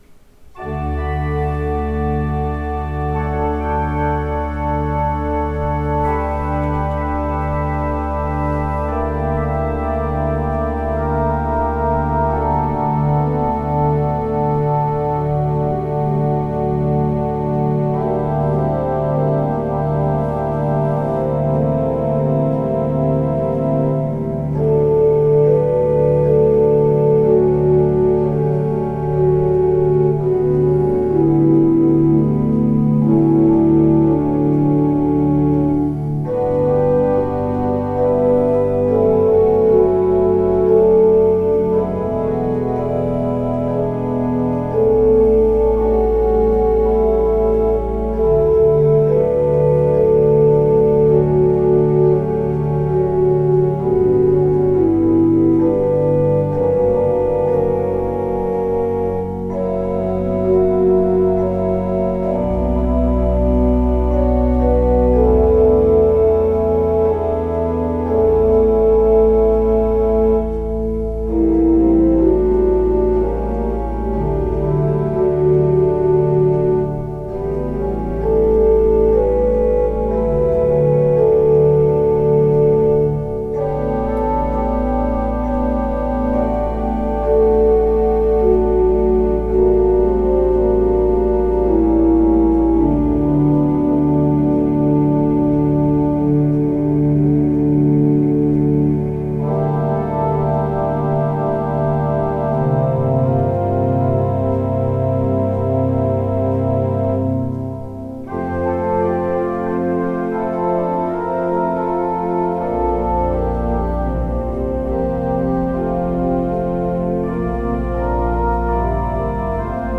I hope you enjoy this prelude.